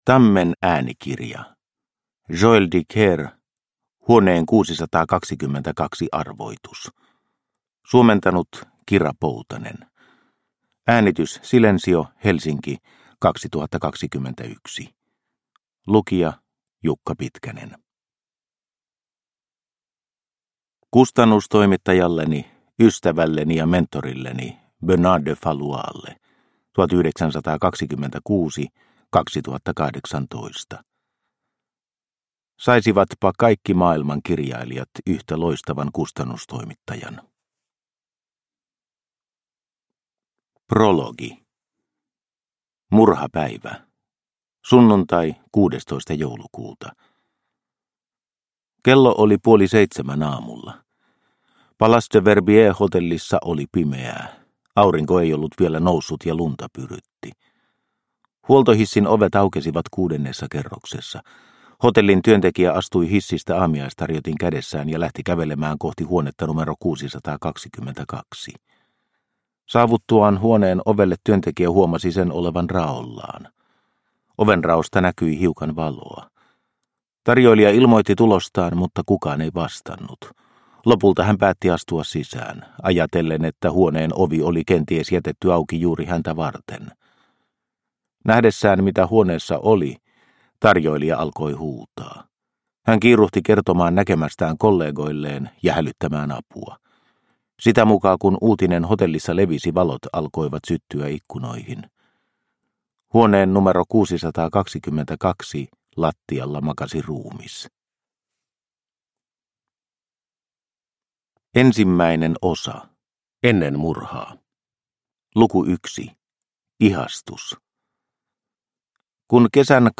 Huoneen 622 arvoitus – Ljudbok – Laddas ner